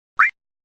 final-fantasy-cursor-sound.CHjN2Siw.mp3